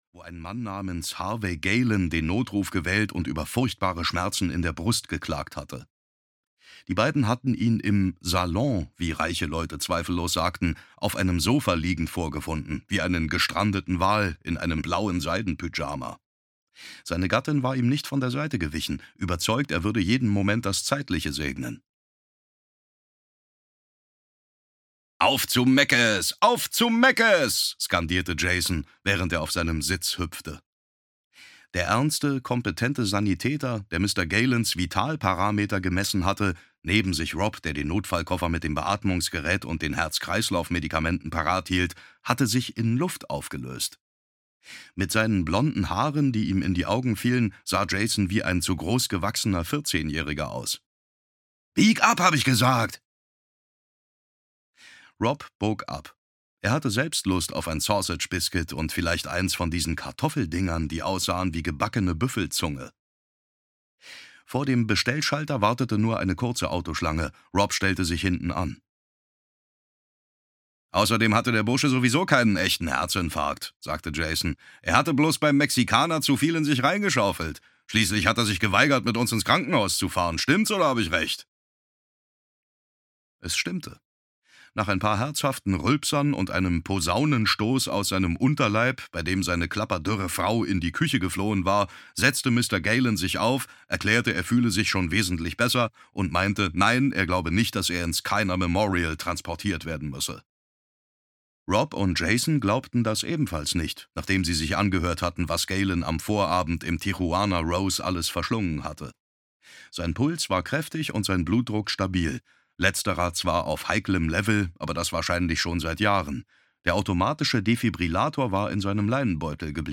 Mind Control (DE) audiokniha
Ukázka z knihy
• InterpretDavid Nathan